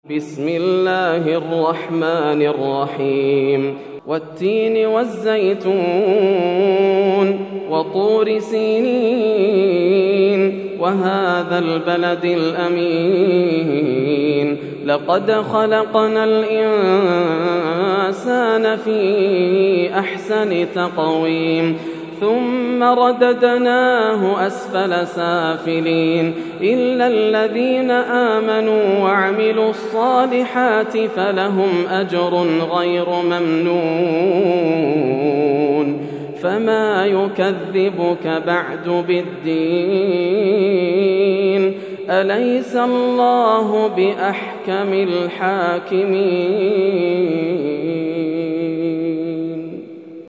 سورة التين > السور المكتملة > رمضان 1431هـ > التراويح - تلاوات ياسر الدوسري